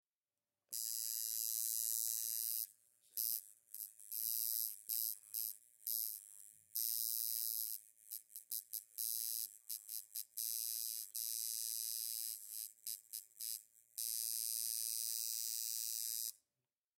На этой странице собраны звуки работы тату-машинки в разных режимах: от мягкого жужжания до интенсивного гудения.
Шум работающей тату машинки